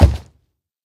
sounds / mob / hoglin / step1.ogg
step1.ogg